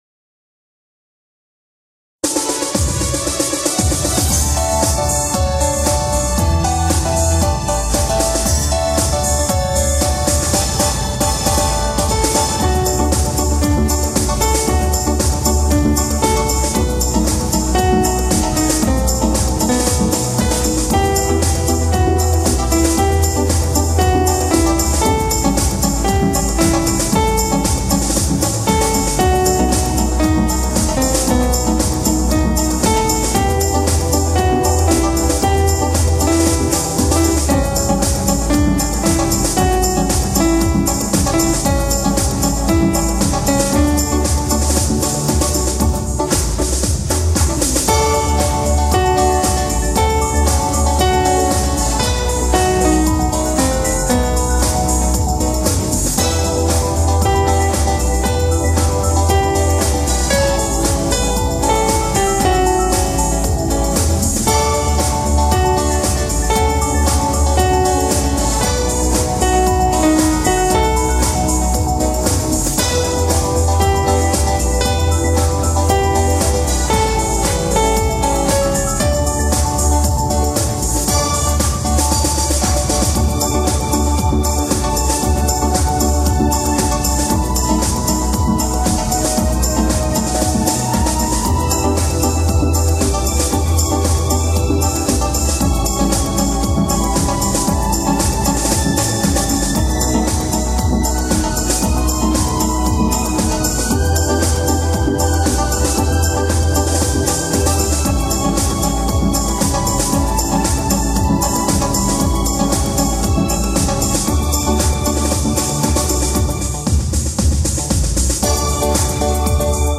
МАМА, ТАТО… (Дитяча.